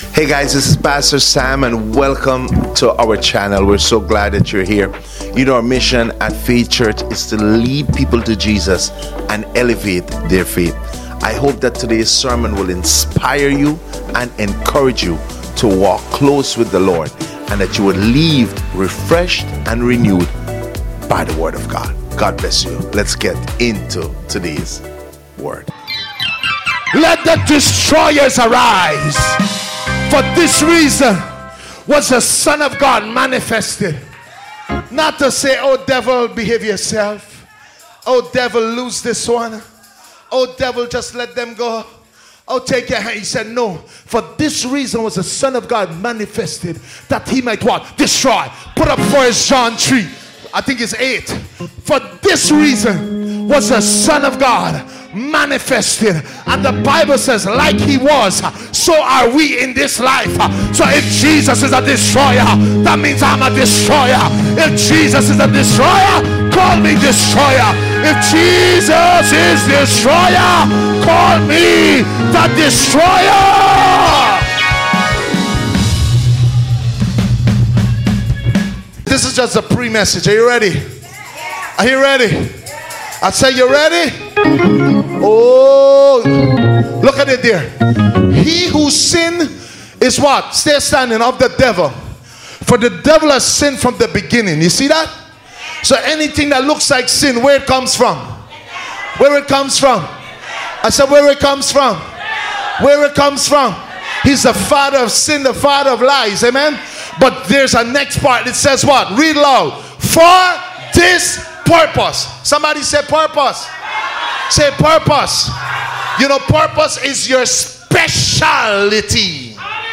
“Only Believe” is a sermon that underscores the transformative power of faith in Jesus Christ, inspired by the story of Jairus’s daughter (Mark 5:21-43).